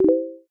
Сообщение Чата на Facebook